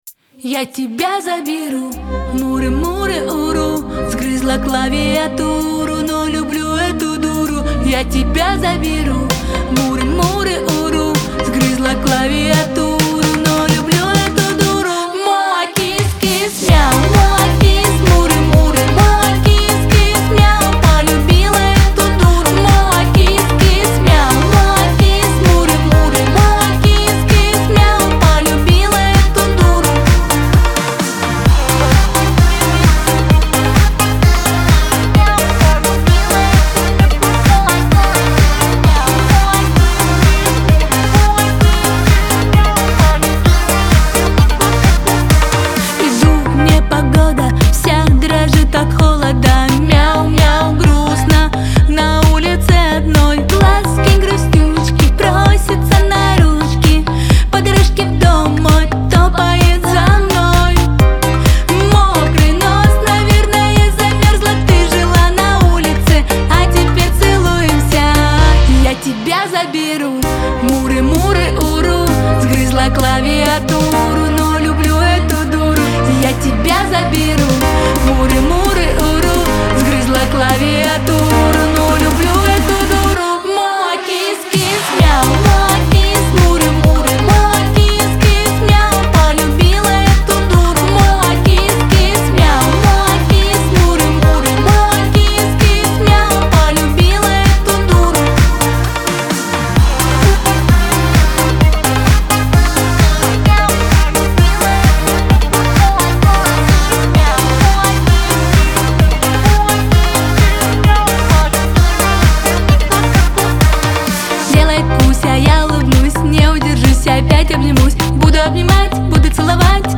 весёлая музыка
pop
диско